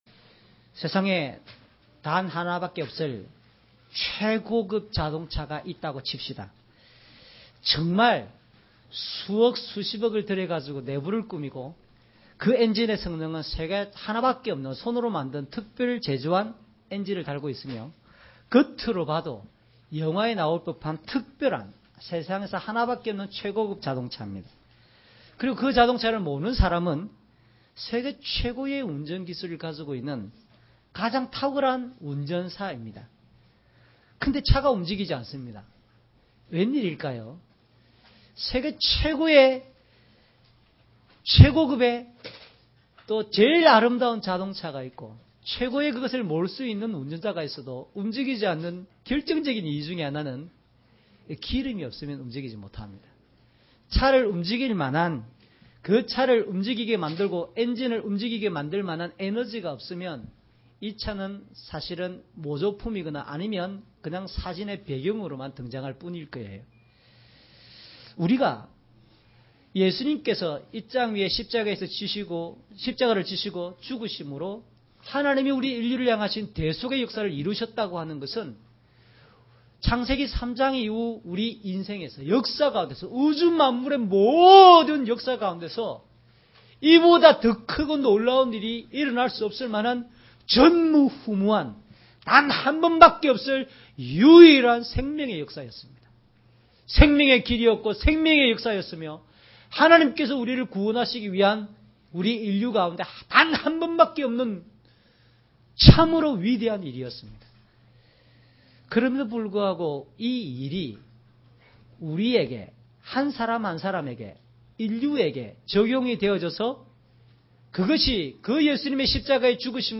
주일설교 - 10년 10월 17일 "은혜를 이어가는 방편이 있습니다."(눅24:1-35)